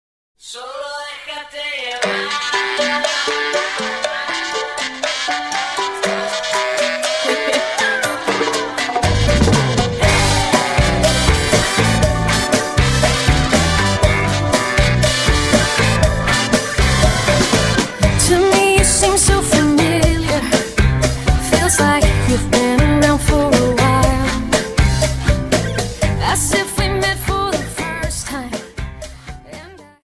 Dance: Cha Cha 31 Song